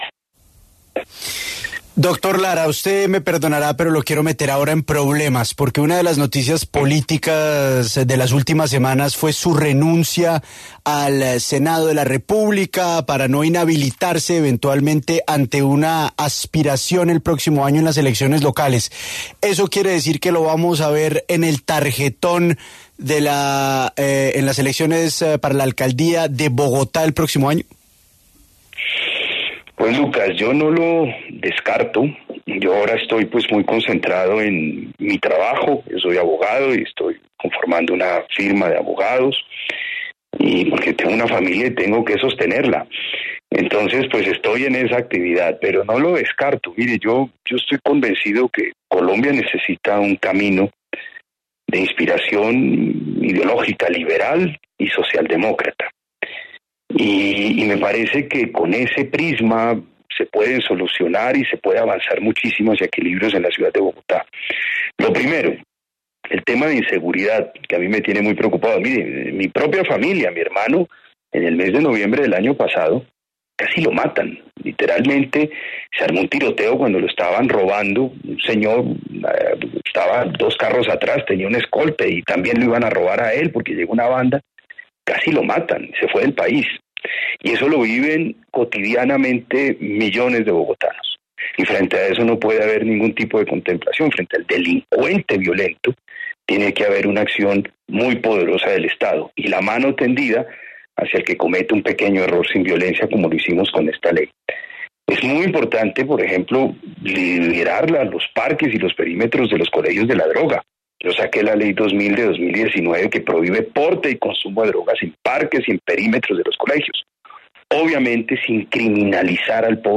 En diálogo con La W, Rodrigo Lara se pronunció sobre su futuro tras su salida del Congreso de la República.